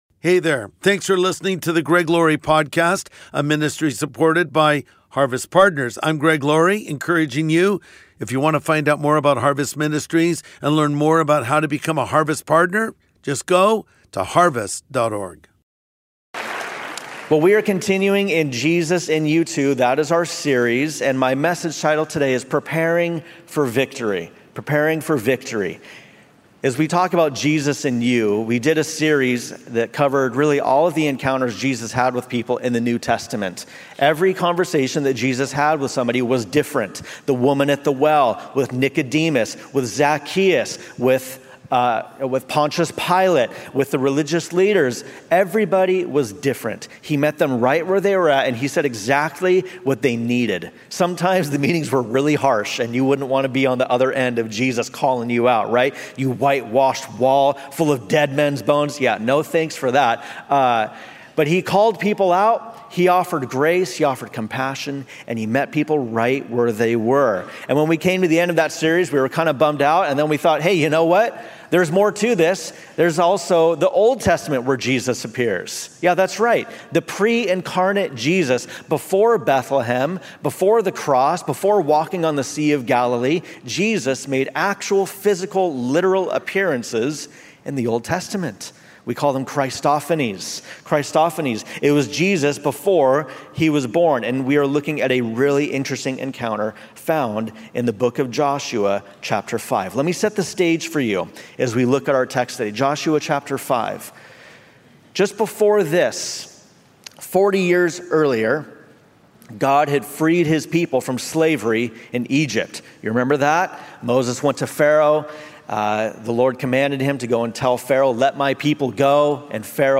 Preparing for Victory | Sunday Message